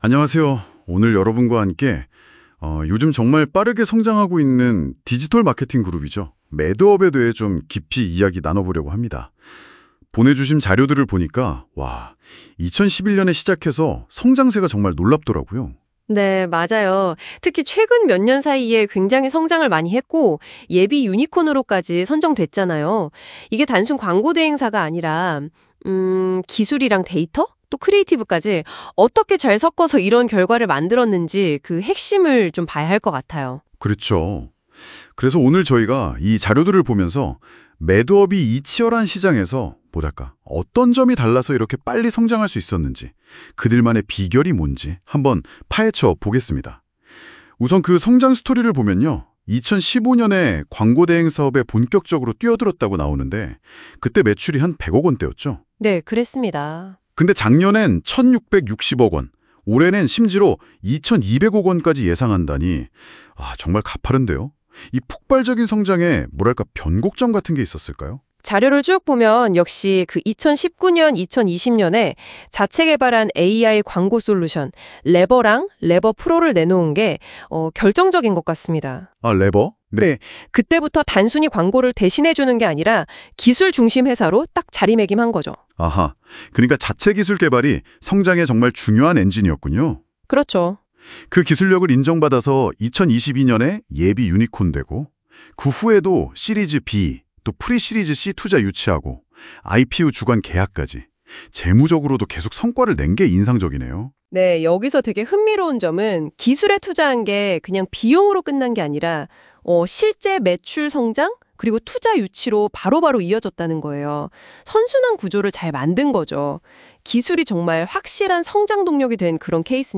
매드업 음성 인터뷰 소개